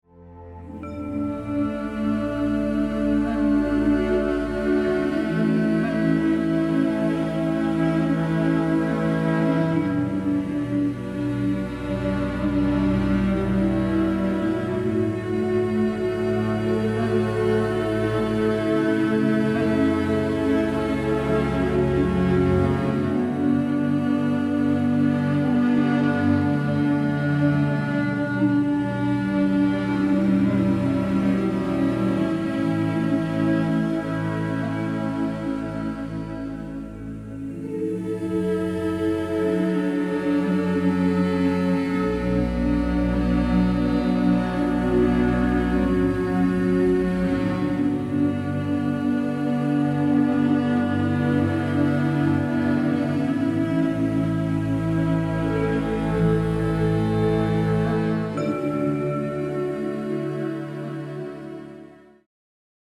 Sphärische Musik zum Eintauchen in friedvolle Welten
Gesang ohne Worte
Meditationsmusik